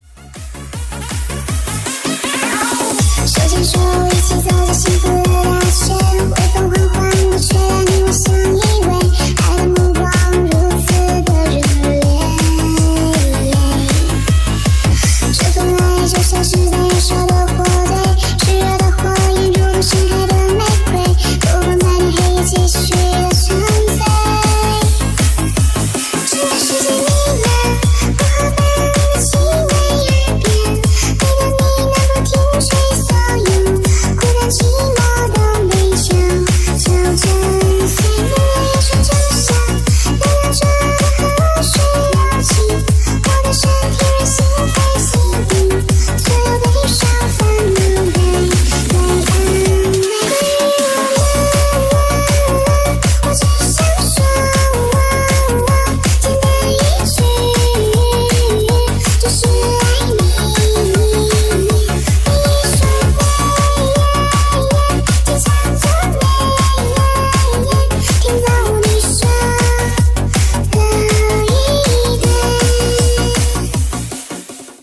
• Качество: 320, Stereo
hardcore
милые
заводные
EDM
быстрые
happy hardcore
Китайский клубнячок